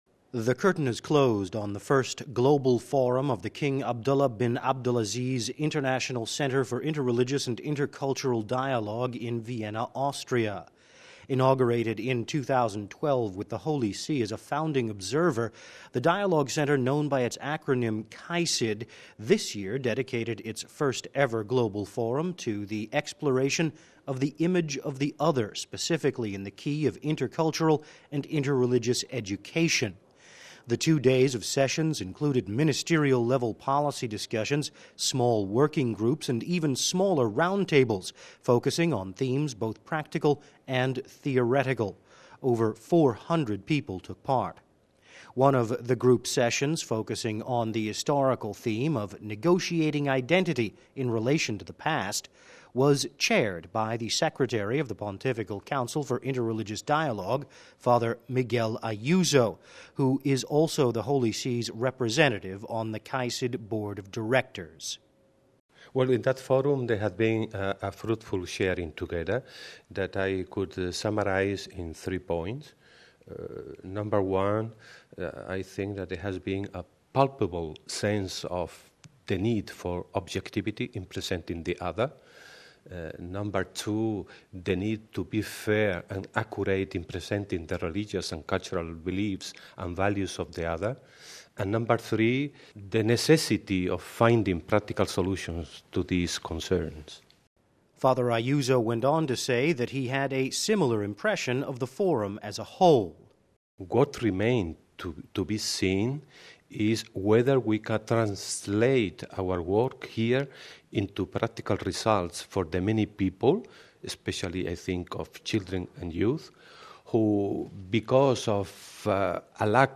“In that forum there was a fruitful sharing,” of ideas and experiences, said Fr. Ayuso in an exclusive interview with Vatican Radio.